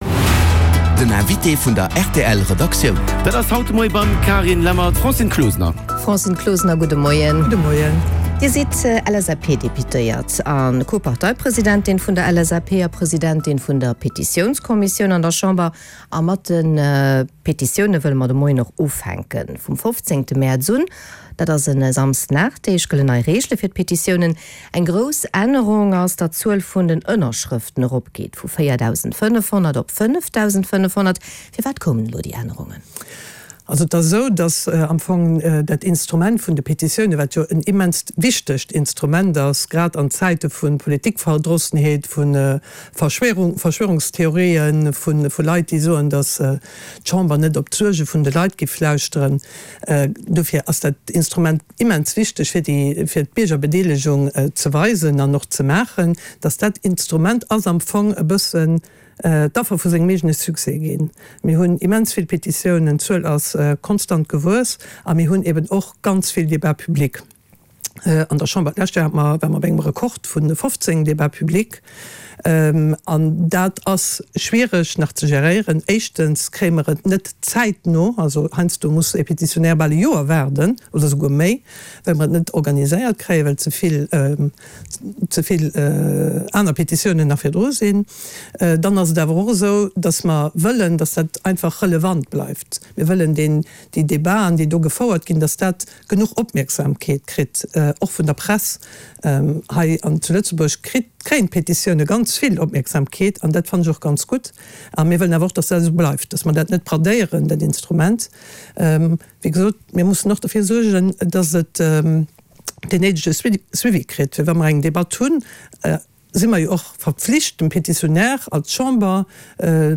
Den Hannergrondinterview mat Vertrieder aus Politik an Zivilgesellschaft